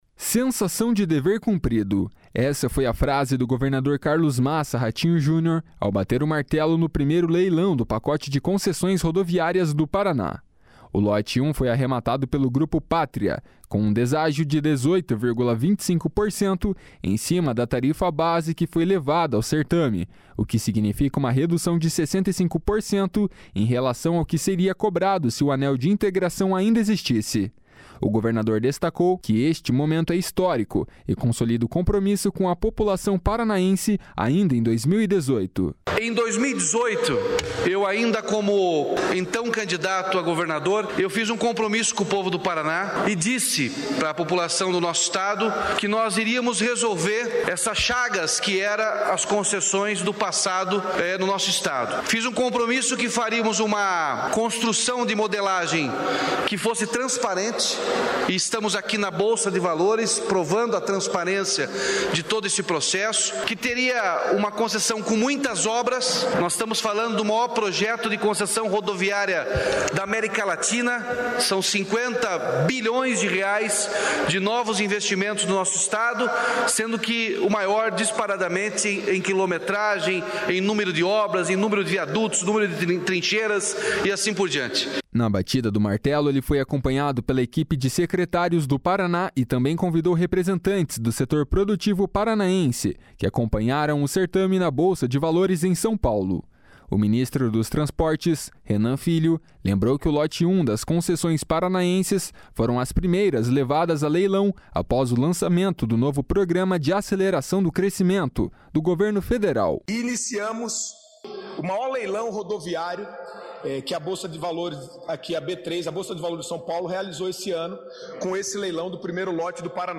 // SONORA RATINHO JUNIOR //
// SONORA RENAN FILHO //